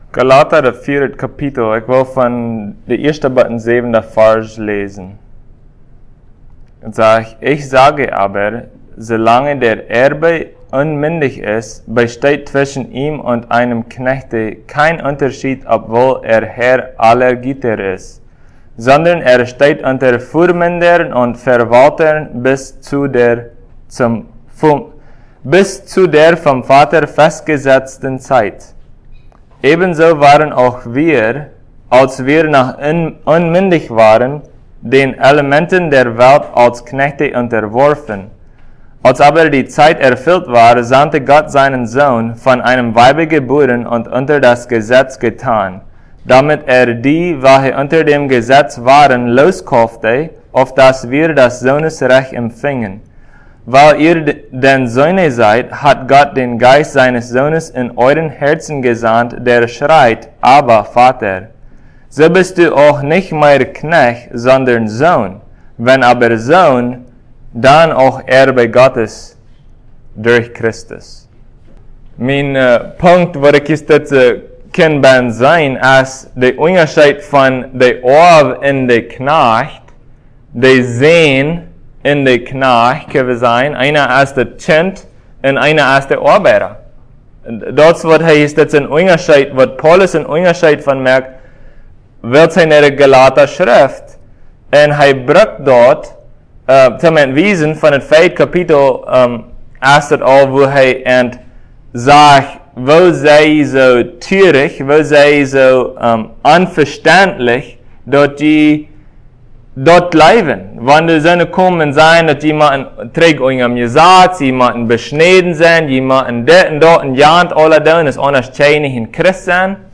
Passage: Galatians 4:1-7 Service Type: Sunday Plautdietsch « What is a Biblical Woman?